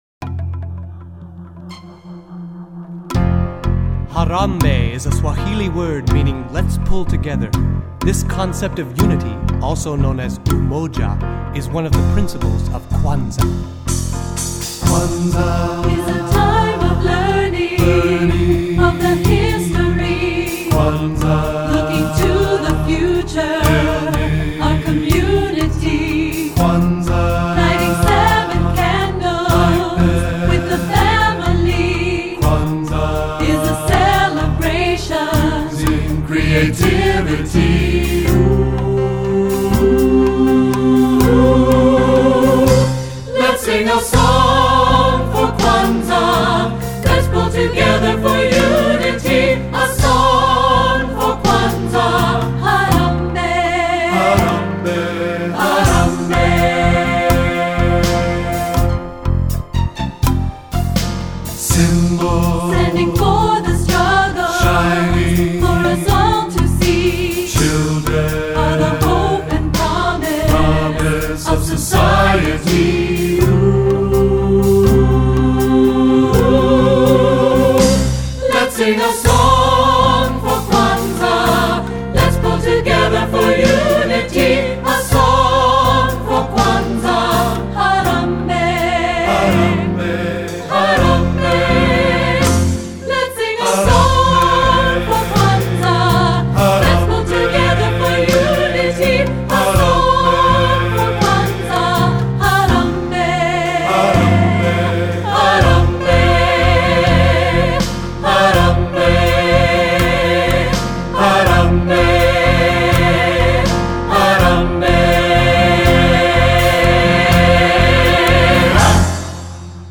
Voicing: 3-Part Mixed